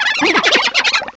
pokeemerald / sound / direct_sound_samples / cries / croagunk.aif